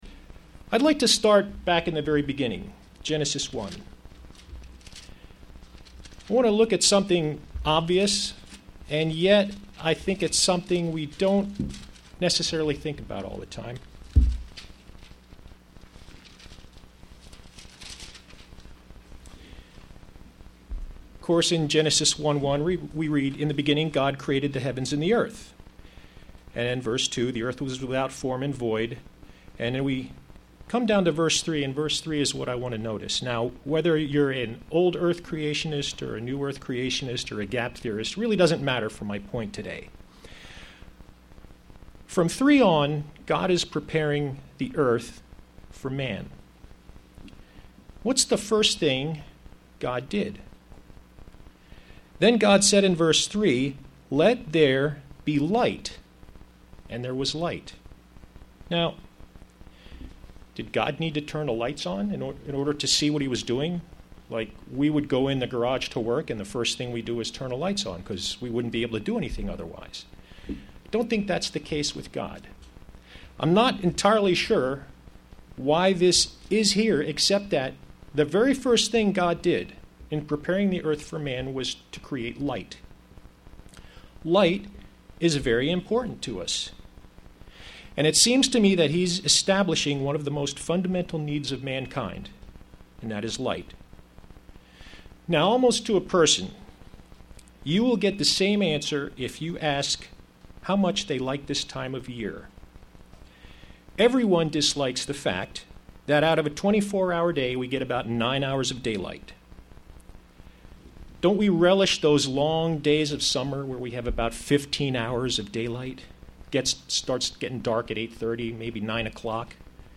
Sermons
Given in York, PA